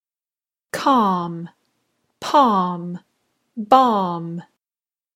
calm-palm-balm.mp3